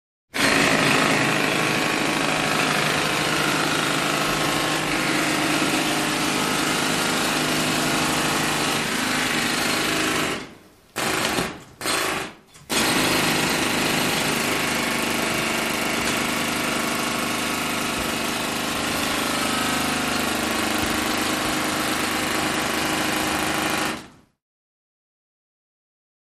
Jackhammer 1; Jackhammer; Hammering Concrete, Steady With Sporadic Breaks, Medium Perspective.